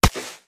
ConcreteHit03.wav